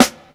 Snares
SNARE_SCHOOLS_OUT.wav